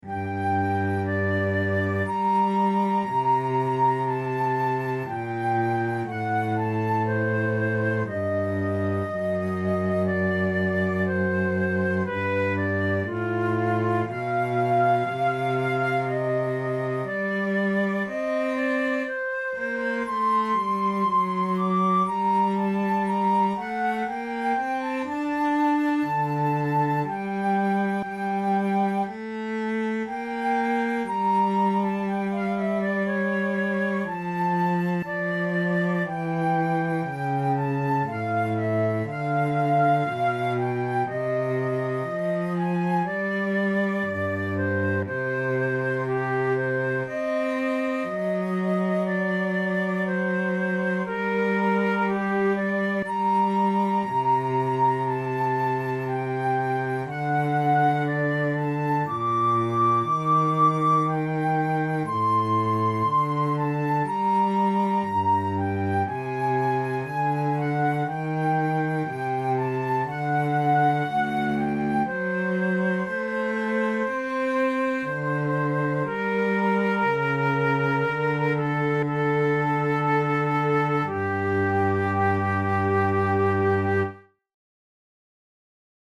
This Adagio in G minor is the third movement of the third Sonata from the Trattenimenti armonici collection by Italian Baroque composer Tomaso Albinoni.
Categories: Baroque Sonatas Difficulty: easy